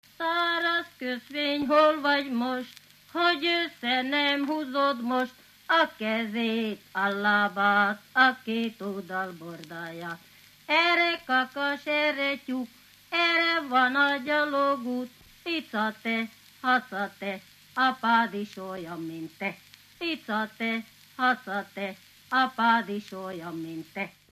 Alföld - Bereg vm. - Nagydobrony
ének
Stílus: 6. Duda-kanász mulattató stílus
Kadencia: 5 (5) 4 1